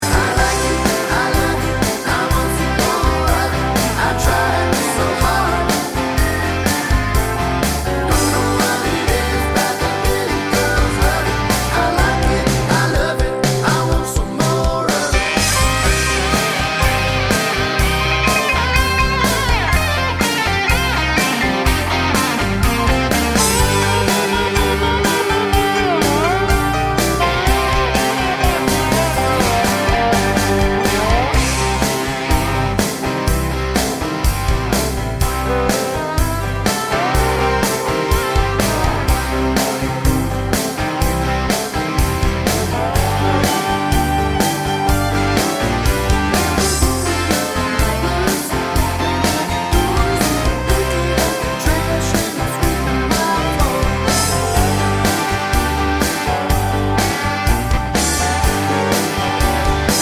no Backing Vocals Country (Male) 3:11 Buy £1.50